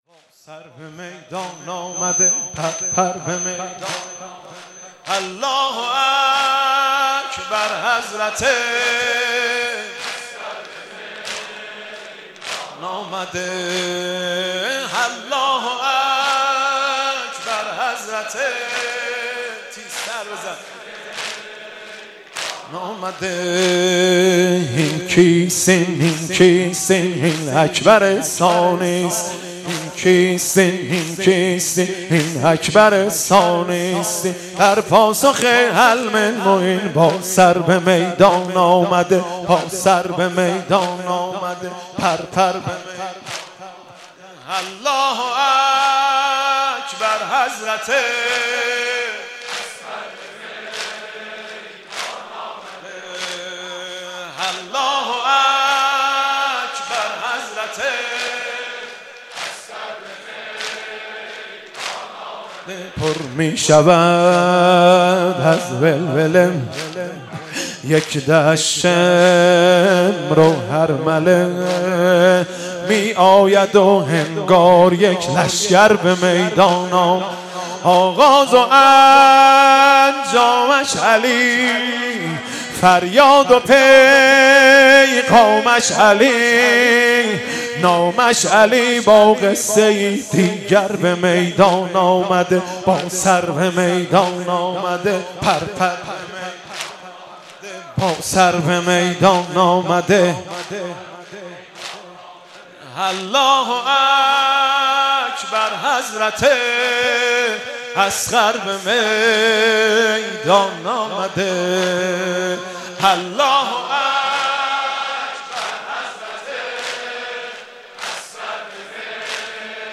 محرم 99 - شب هفتم - زمینه - با سر به میدان آمده